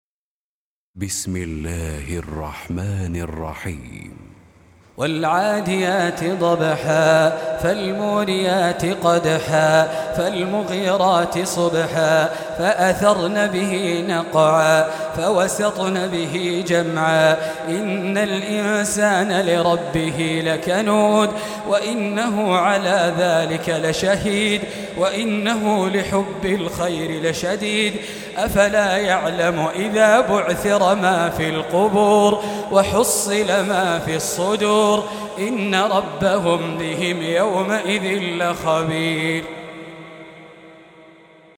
Surah Al-'Adiy�t سورة العاديات Audio Quran Tarteel Recitation Home Of Sheikh Khalid Al-Jileel :: الشيخ خالد الجليل | حفص عن عاصم Hafs for Assem - Audio Quran Reciters, Qur'an Audio, Quranic Audio Download, Quran Video TV Surah Recitations with Sheikh Khalid Al-Jileel
Surah Repeating تكرار السورة Download Surah حمّل السورة Reciting Murattalah Audio for 100. Surah Al-'Adiy�t سورة العاديات N.B *Surah Includes Al-Basmalah Reciters Sequents تتابع التلاوات Reciters Repeats تكرار التلاوات